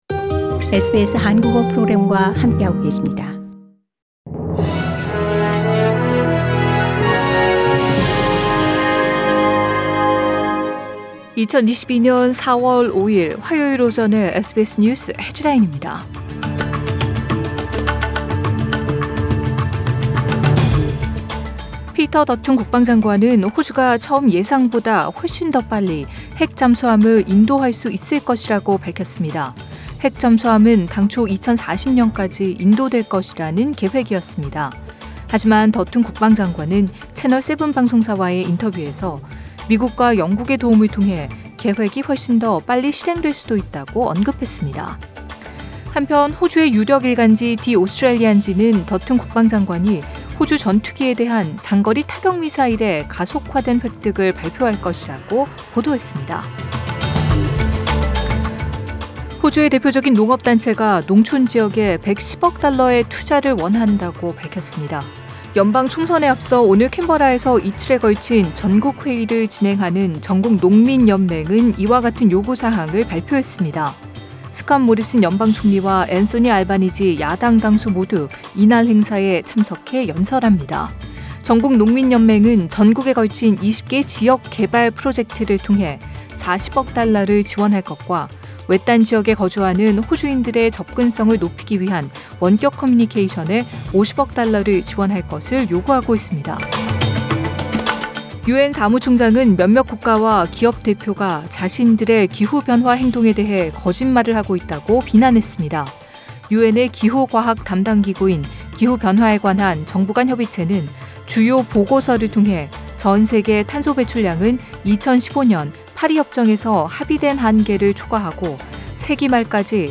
2022년 4월 5일 화요일 오전의 SBS 뉴스 헤드라인입니다.